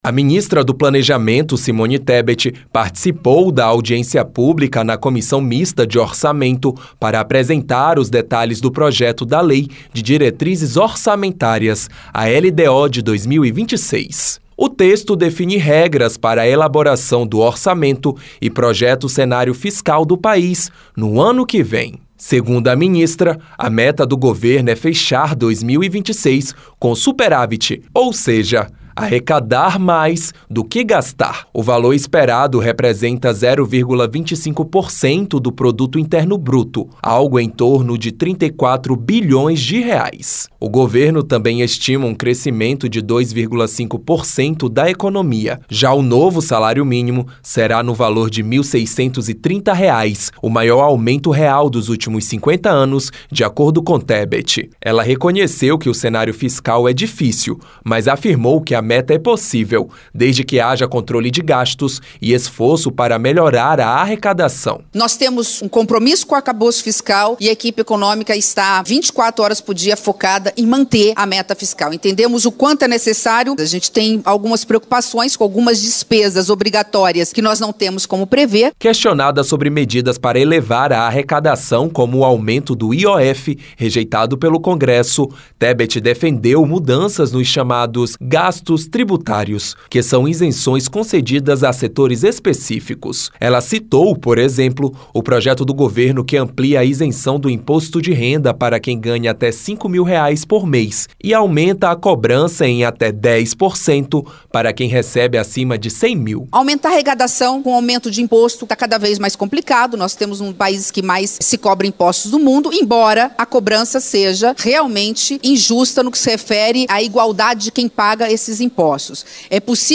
A ministra do Planejamento, Simone Tebet, afirmou na terça (8) que a meta fiscal para 2026 pode ser alcançada. Durante audiência na Comissão Mista de Orçamento (CMO), ela apresentou o projeto da Lei de Diretrizes Orçamentárias ( PLN 2/2025 ), que prevê crescimento de 2,5% na economia, superávit de 0,25% do PIB e salário mínimo de R$ 1.630,00. Tebet defendeu revisão nos gastos tributários, proveniente de um grande número de isenções fiscais, e disse que a proposta do governo é realista.